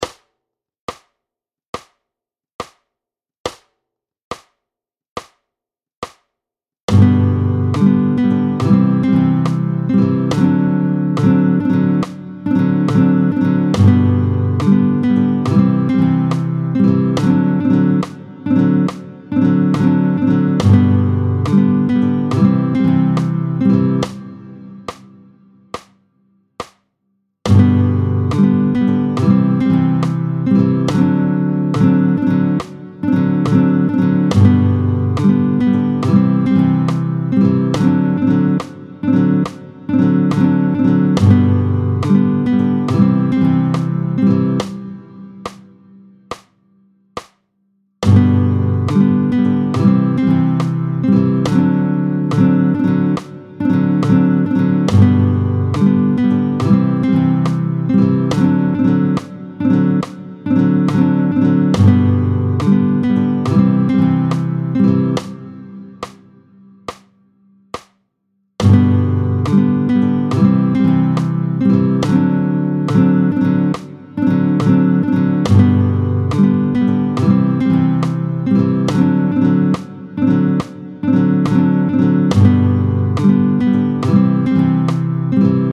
Rythmique
tempo 70